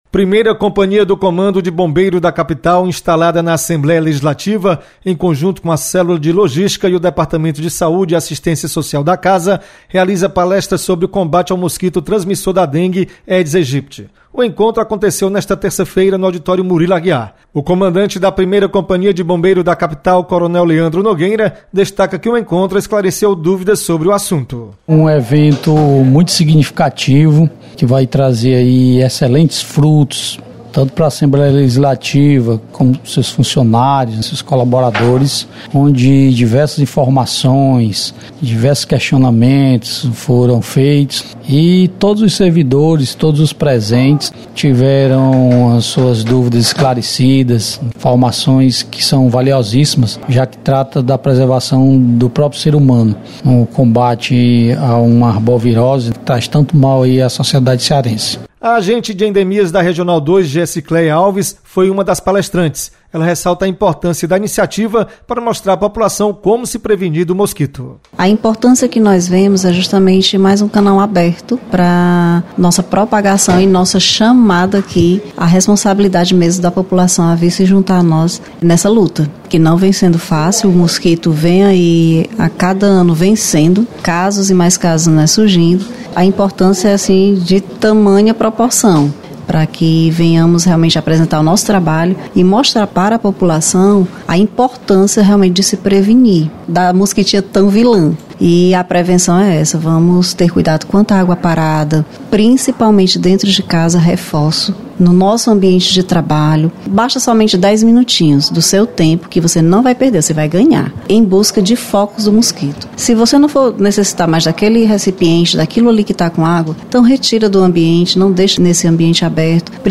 Palestra